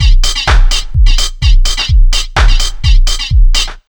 127BEAT4 3-R.wav